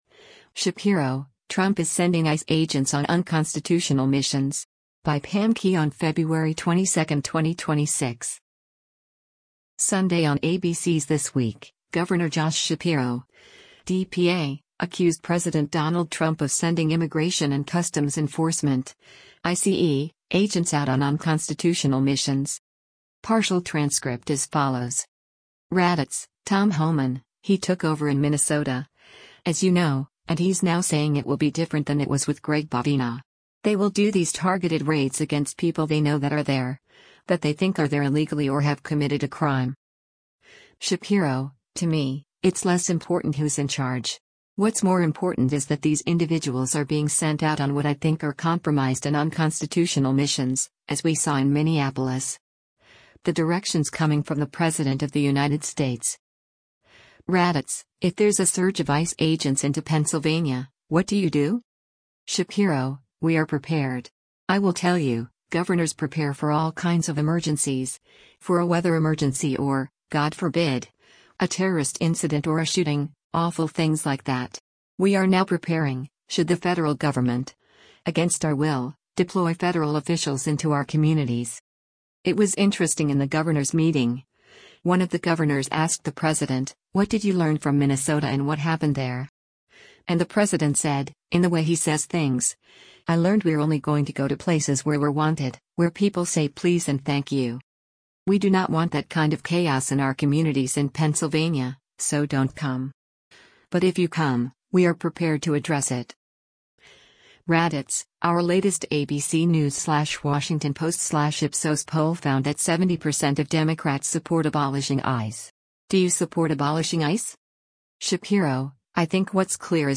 Sunday on ABC’s “This Week,” Gov. Josh Shapiro (D-PA) accused President Donald Trump of sending Immigration and Customs Enforcement (ICE) agents out on “unconstitutional missions.”